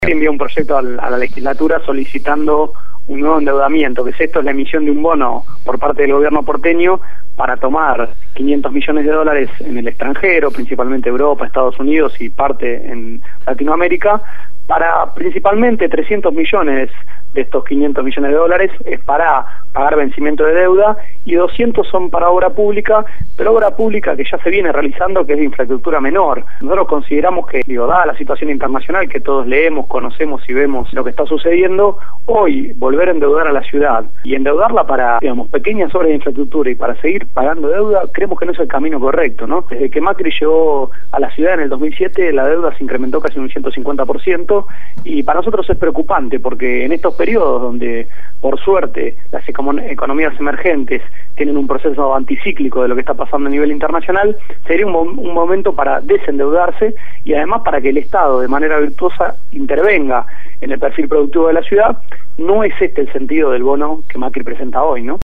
Lo dijo Gonzalo Ruanova, legislador porteño, en el programa Abramos la boca (Lunes a viernes de 16 a 18 horas) de Radio Gráfica FM 89.3